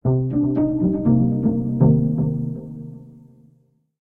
ジングル[シンセ系](7) シンセディレイ系